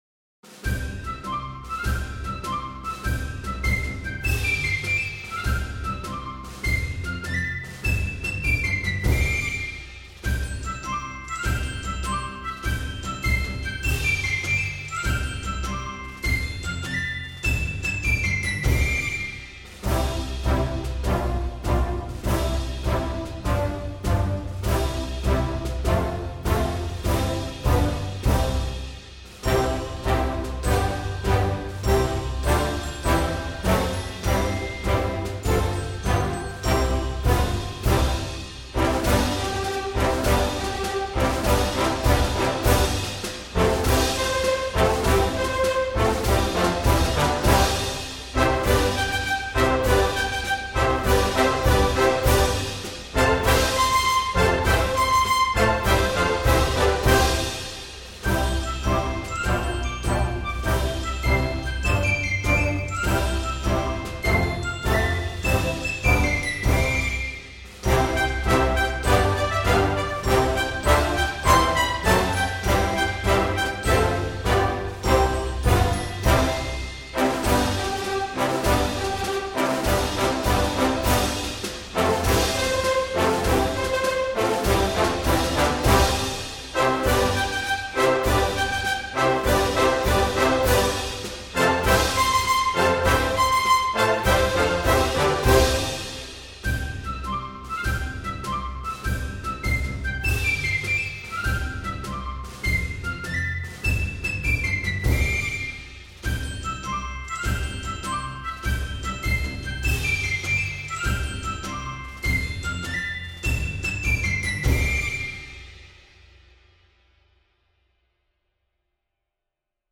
Marschmusik